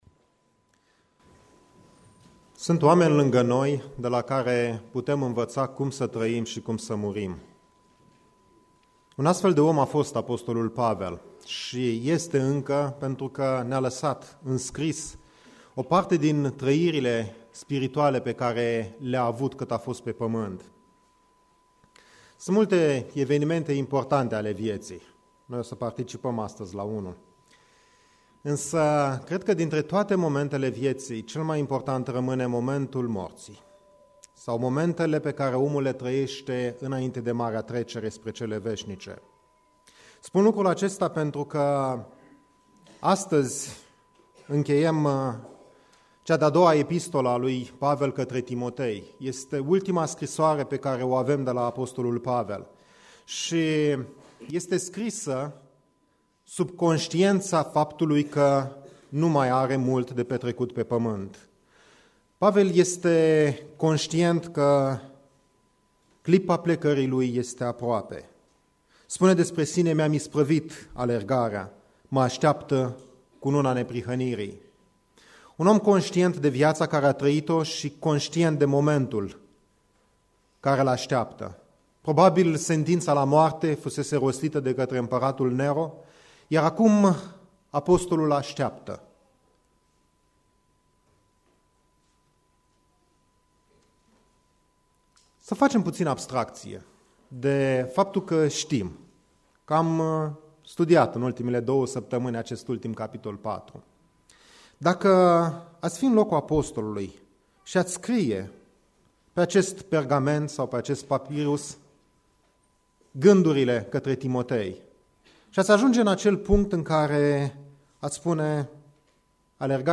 Predica Exegeza 2 Timotei 4b